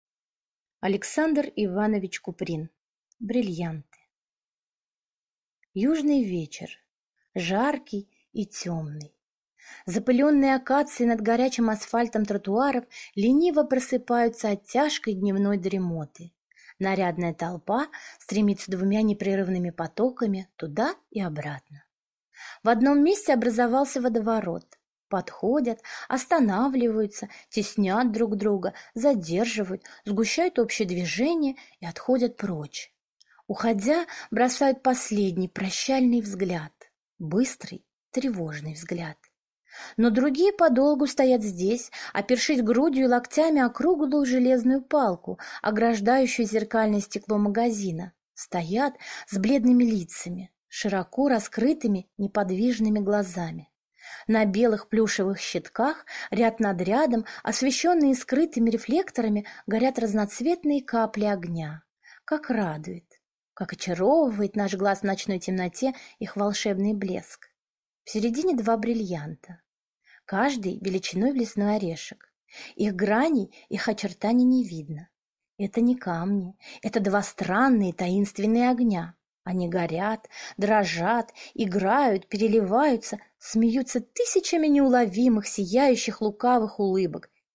Аудиокнига Брильянты | Библиотека аудиокниг